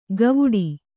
♪ gavuḍi